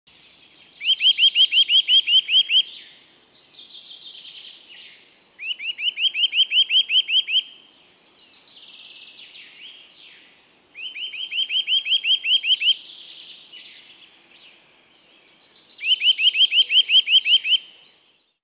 la Sittelle torchepot (cris)
Le chant ressemble à des cris en accéléré. Tonalité et puissance distinguent la sittelle en tous temps.
Sittelle_cris_MN1.mp3